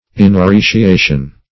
Inappreciation \In`ap*pre"ci*a"tion\, n.